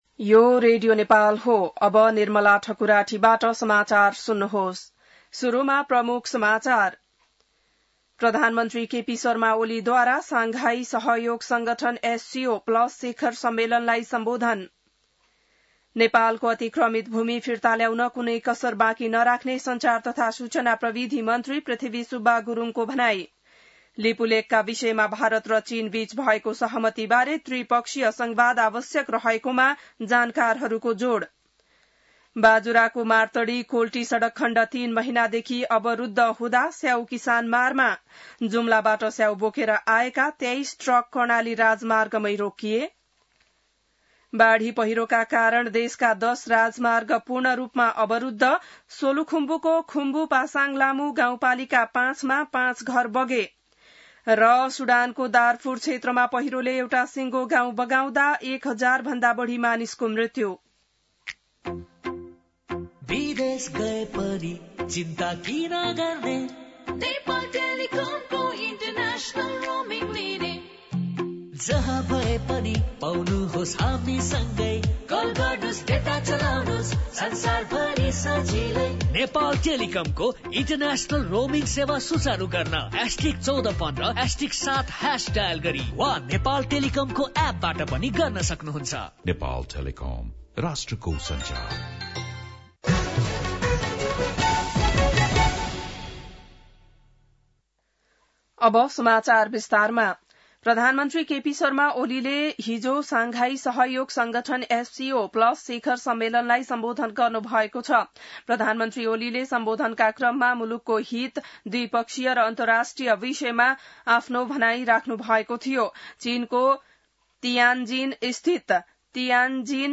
बिहान ७ बजेको नेपाली समाचार : १७ भदौ , २०८२